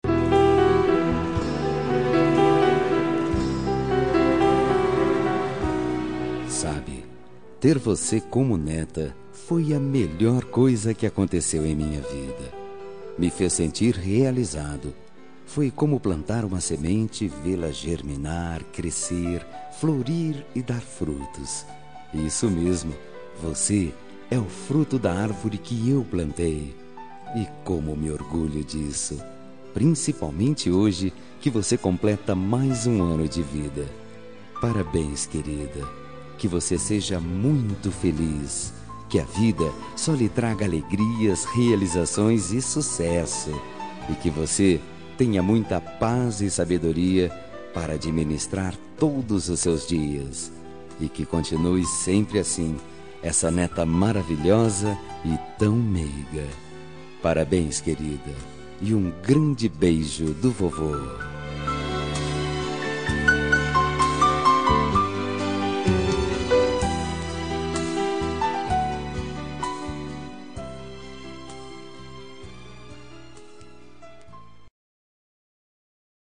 Aniversário de Neta – Voz Masculina – Cód: 131036